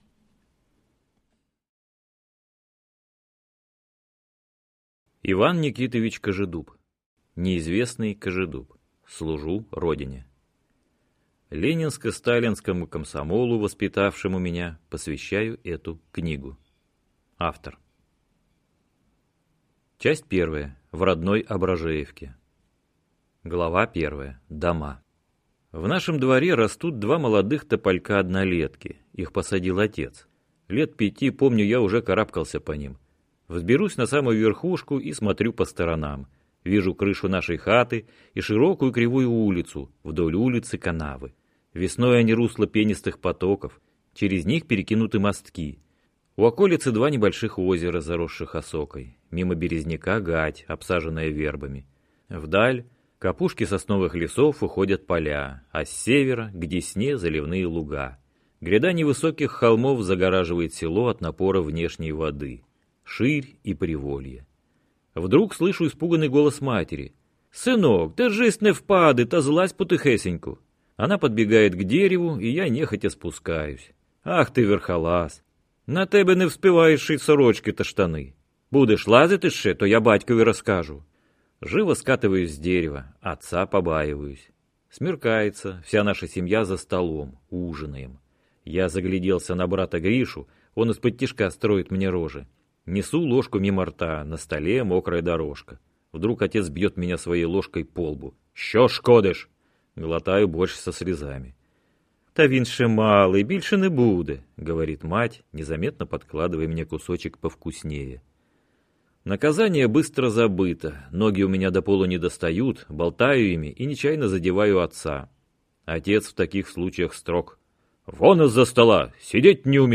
Аудиокнига Неизвестный Кожедуб. Служу Родине!